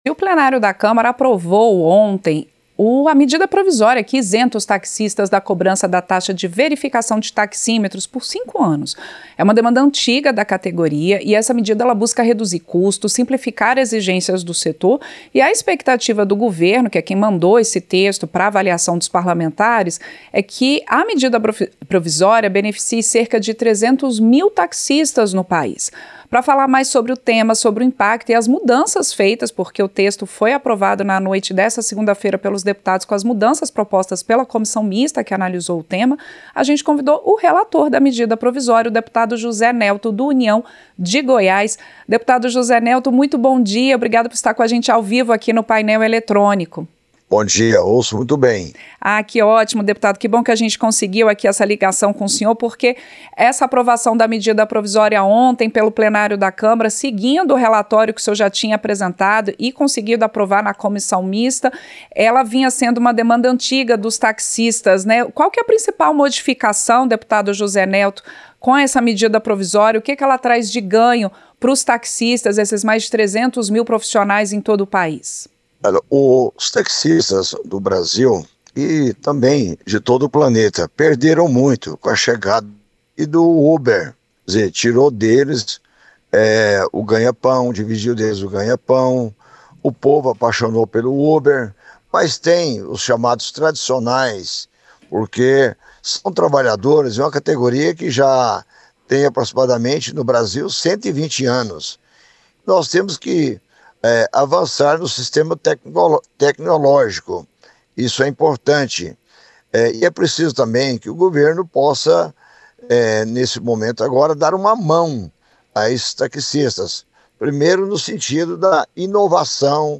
• Entrevista - Dep. José Nelto (União-GO)
Em entrevista ao Painel Eletrônico (28), na manhã seguinte à aprovação da proposta, o relator, deputado José Nelto (União-GO), lembrou que a isenção e outras mudanças aprovadas pelos parlamentares representam um alívio nas contas dos taxistas, impactados nos últimos anos pelo surgimento de serviços de transporte por aplicativo.
Programa ao vivo com reportagens, entrevistas sobre temas relacionados à Câmara dos Deputados, e o que vai ser destaque durante a semana.